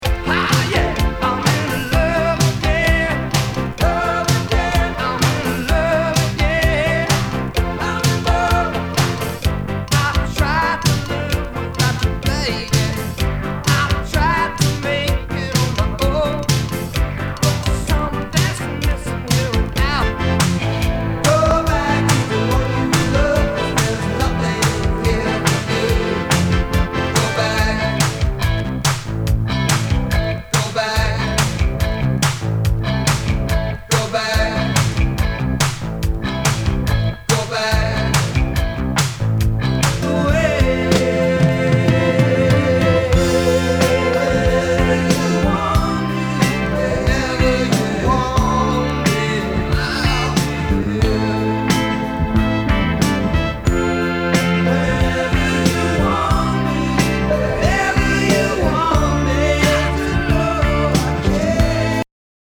ROCK/POPS/INDIE
80’s Rock！！
[VG ] 平均的中古盤。スレ、キズ少々あり（ストレスに感じない程度のノイズが入ることも有り）